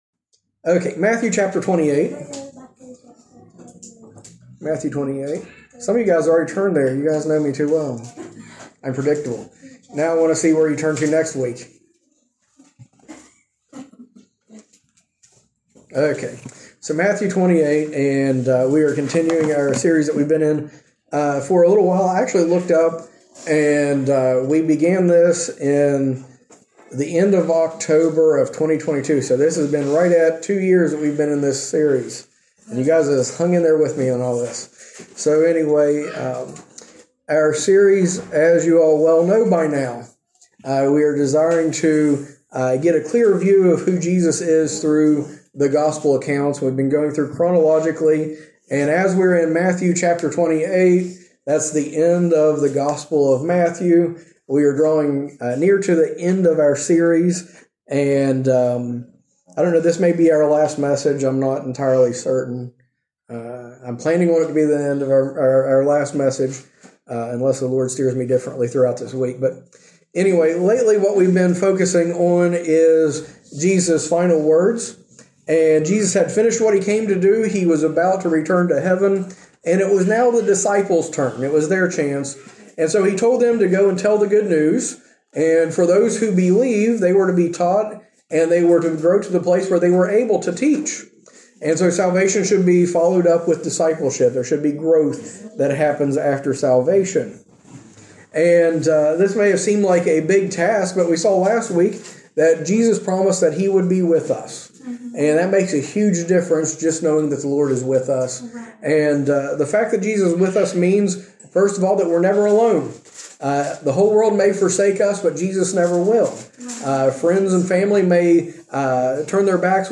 A message from the series "Refocus on Christ."